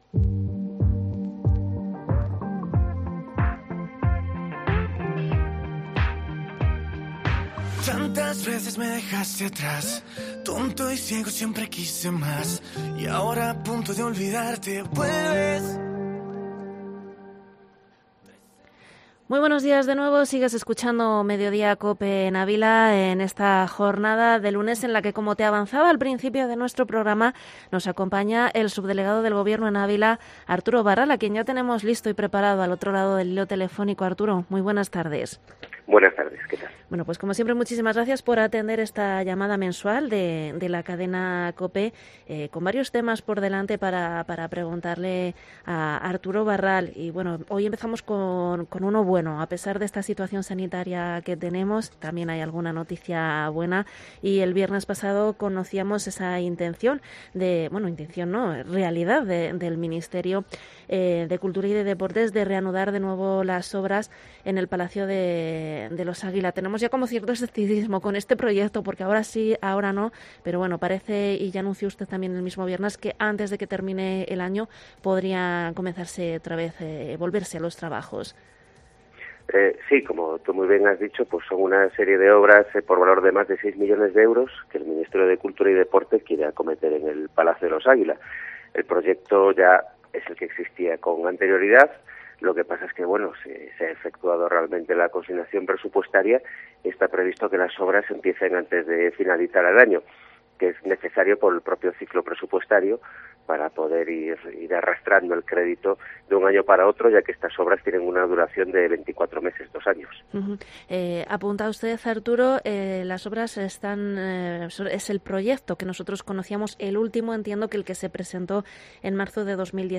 Entrevista al subdelegado del Gobierno en Ávila, Arturo Barral. 16/11/2020